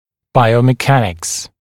[ˌbaɪəumɪˈkænɪks][ˌбайоумиˈкэникс]биомеханика